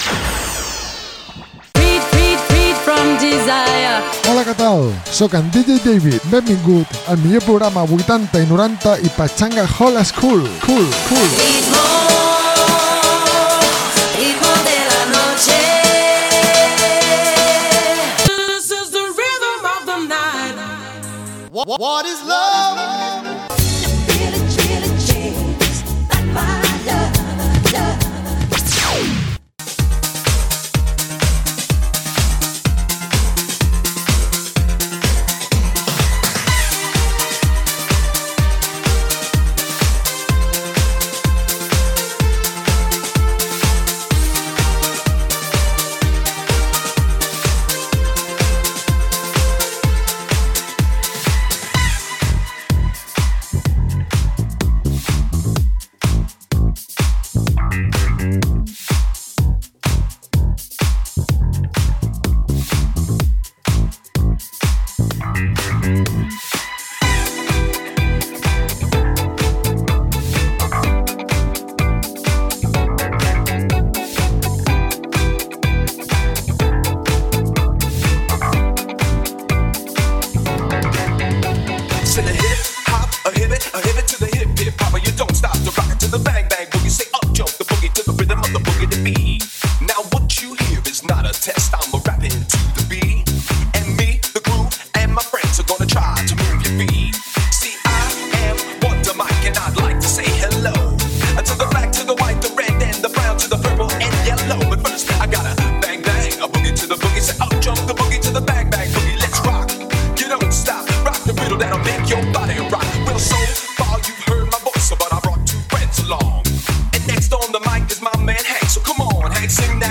Pop, Dance, Rock, Hip Hop, R´n´B, etc.